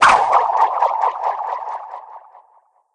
Whirling.wav